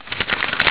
paper.au